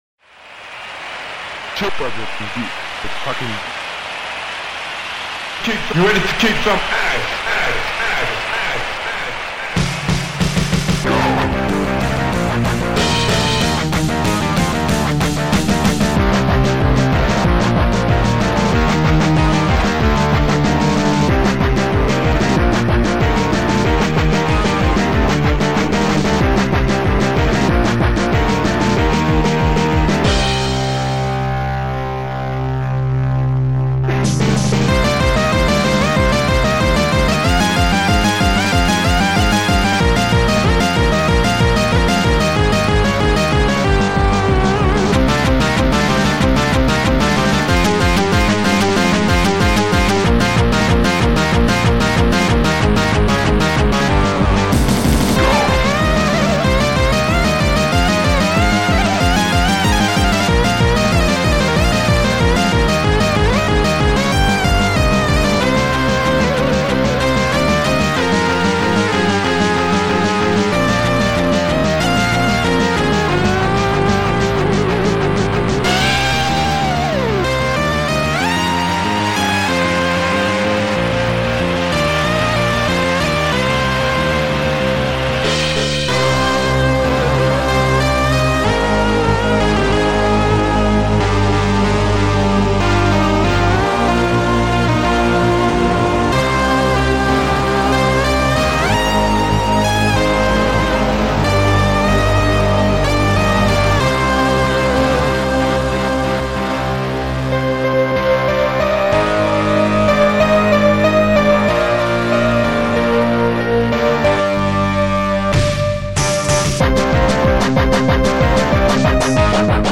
Žánr: Indie/Alternativa